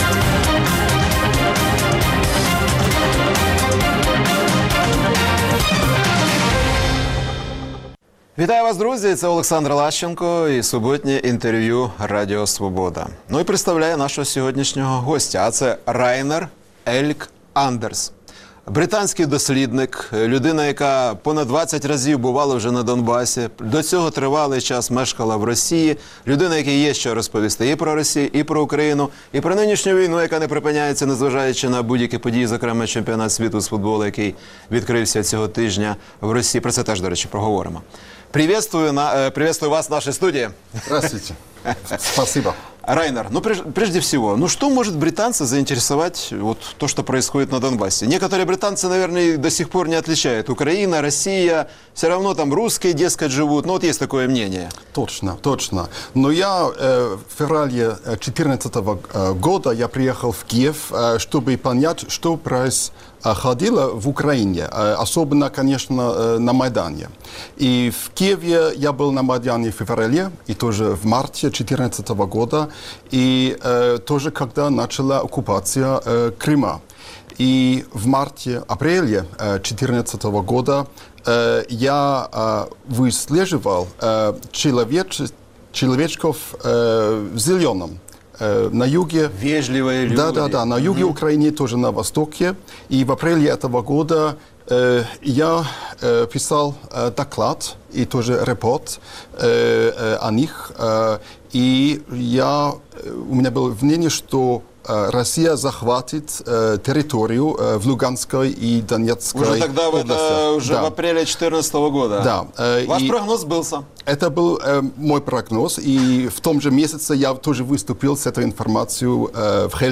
Суботнє інтерв’ю